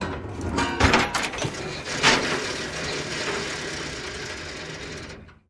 Звуки якоря
Звук якорной цепи спускаемой на корабле